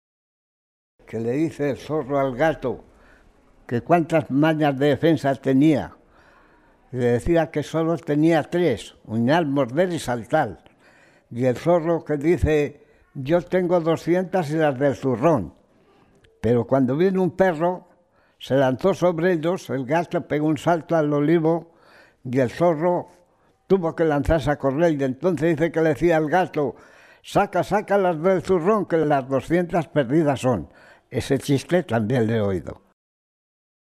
Clasificación: Cuentos
Lugar y fecha de recogida: Igea, 1 de octubre de 2000